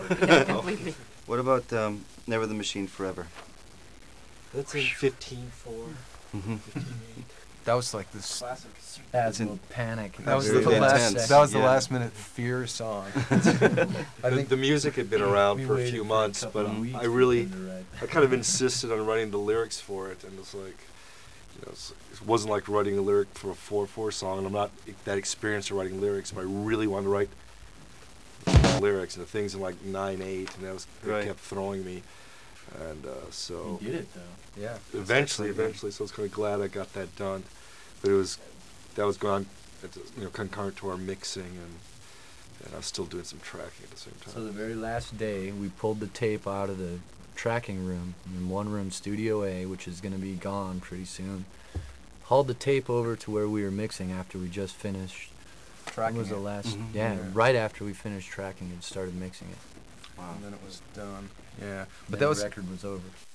The band discusses "Never The Machine Forever" 1,409,764